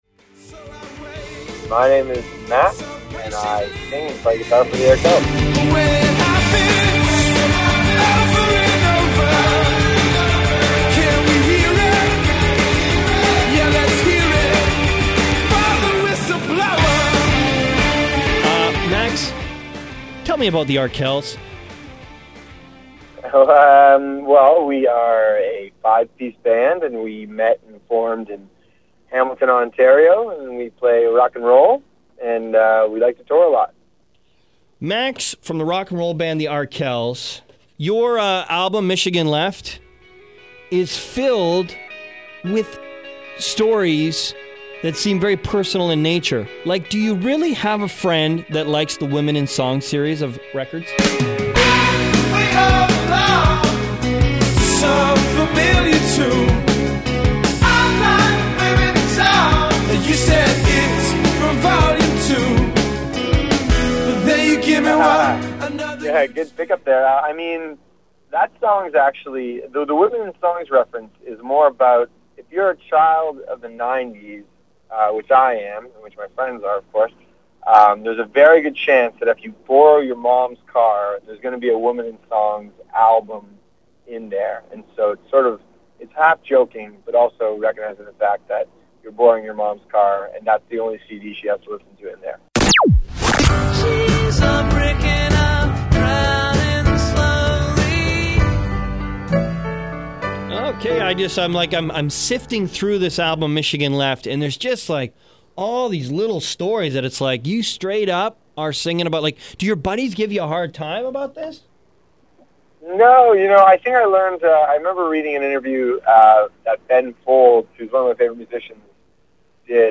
Here is the edited conversation.